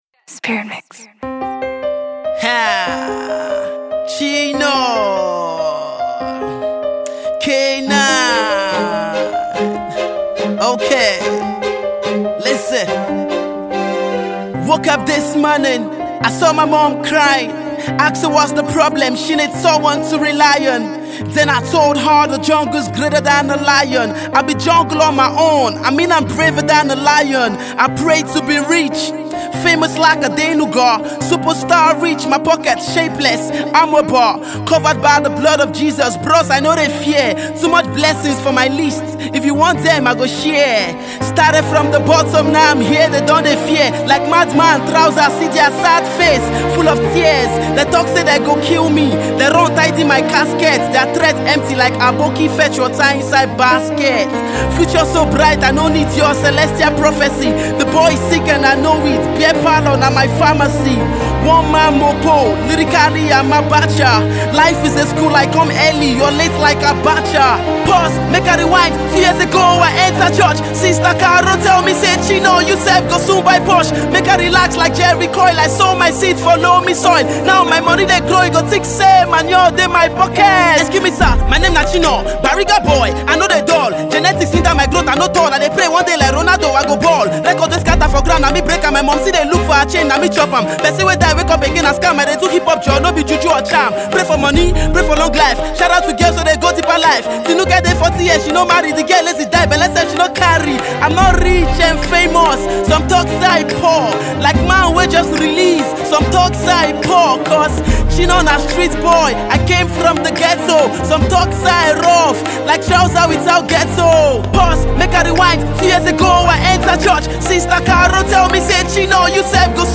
Rap
an Introspective Track